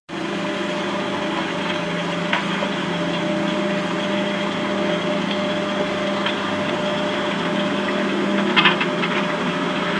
Звук холодильника